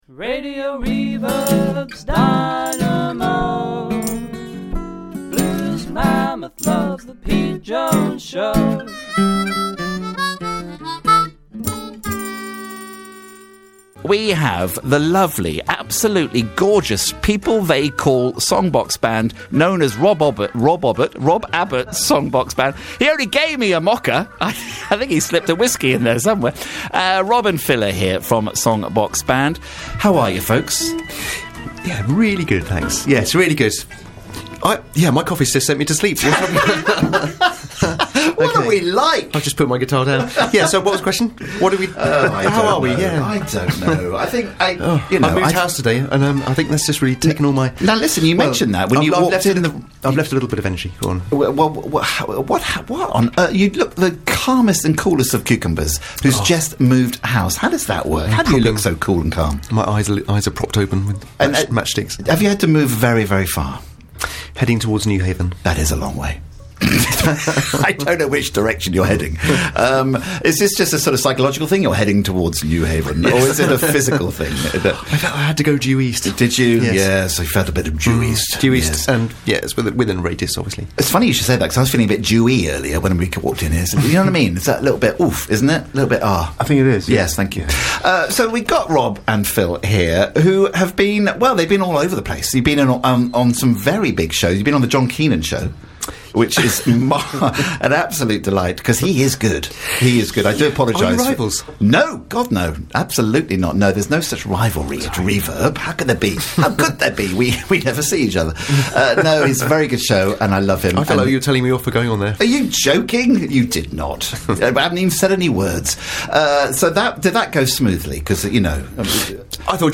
in the studio with us chatting all things
playing 3 absolute beauts live